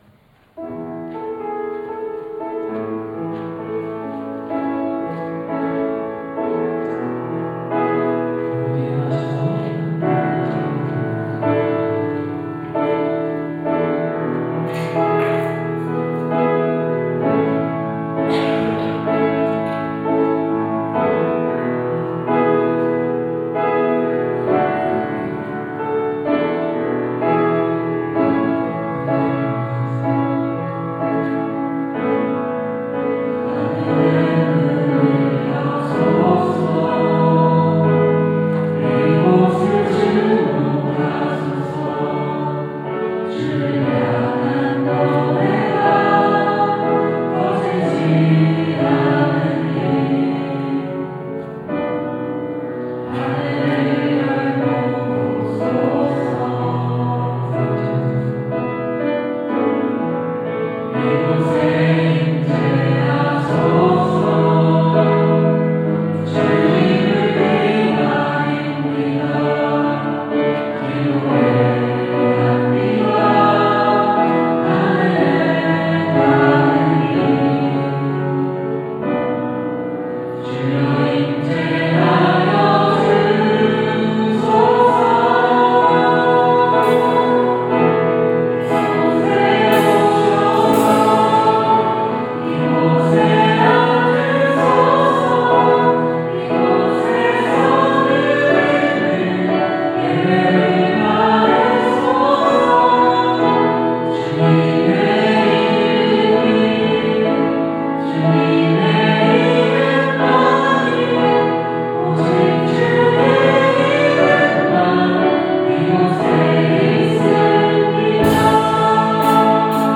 2021년 10월 10일 주일찬양